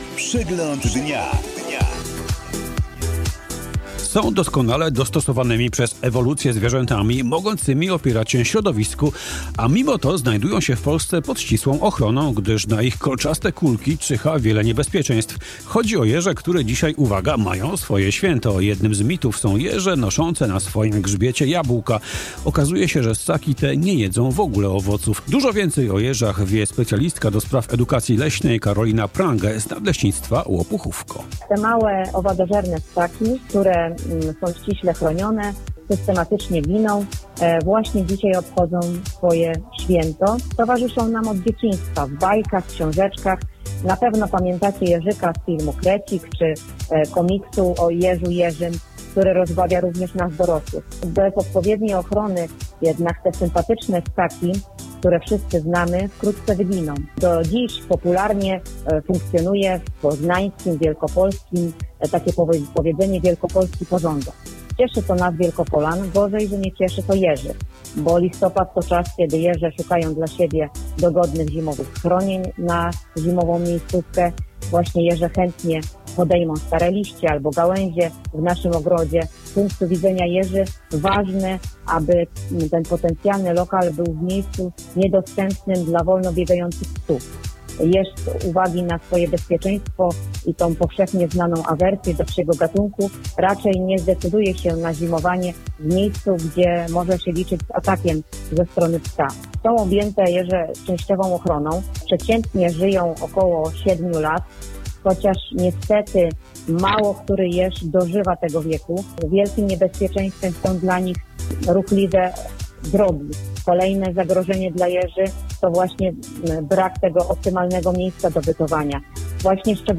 O jeżach wspomniało również Radio Wielkopolska w specjalnej jeżowej audycji.